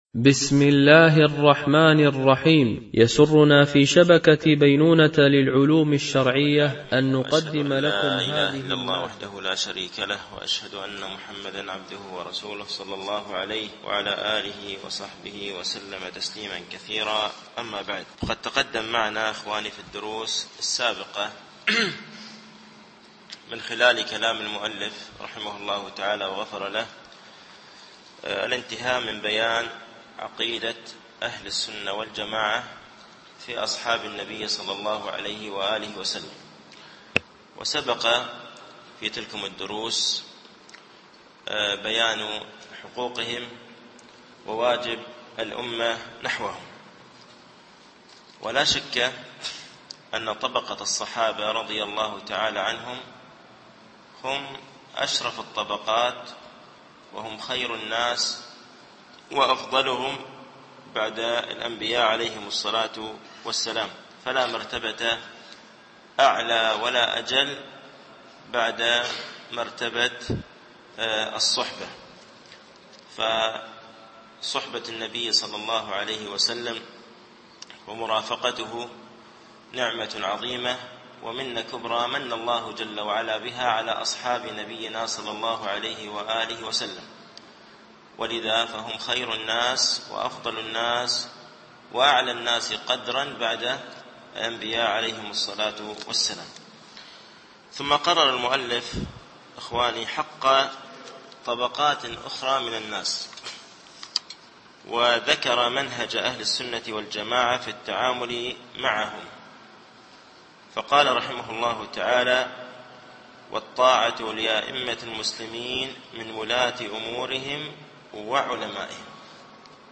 شرح مقدمة ابن أبي زيد القيرواني ـ الدرس الخامس و الثمانون